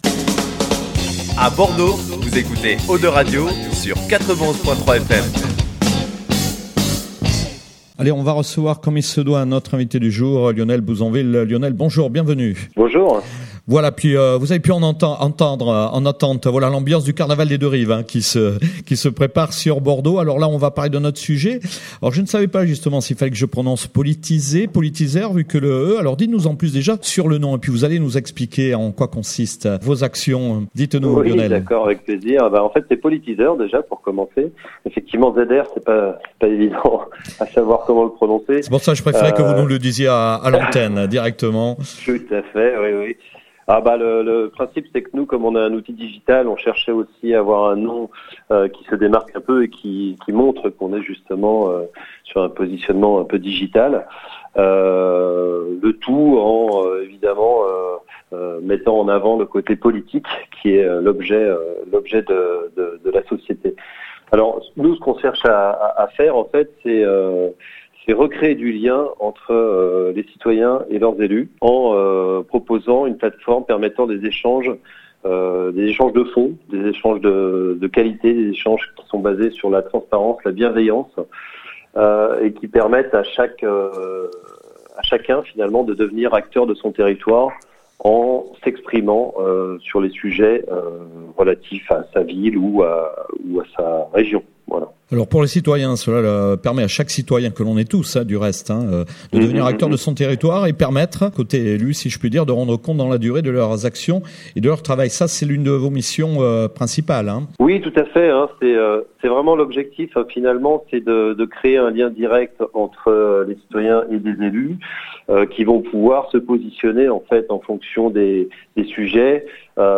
24/02/2018 : Interview GNIAC/02 Radio : découvrez la civictech POLITZR